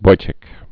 (boichĭk)